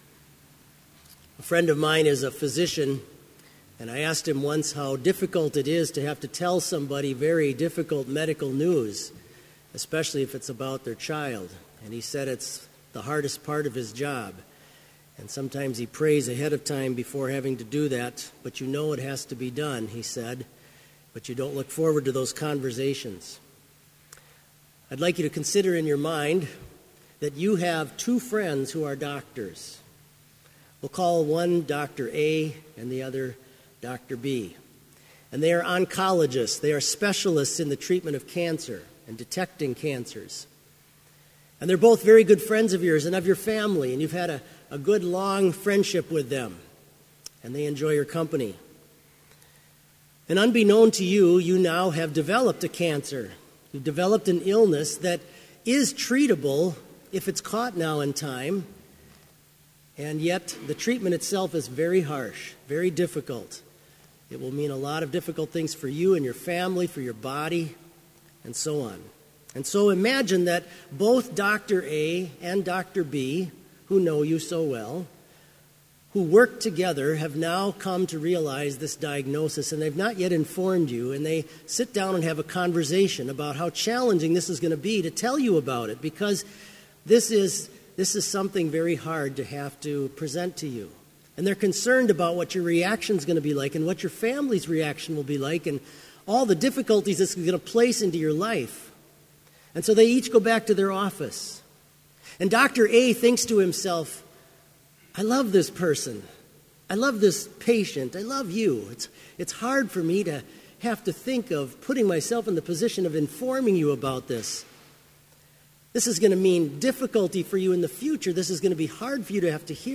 Sermon Only
This Chapel Service was held in Trinity Chapel at Bethany Lutheran College on Friday, October 7, 2016, at 10 a.m. Page and hymn numbers are from the Evangelical Lutheran Hymnary.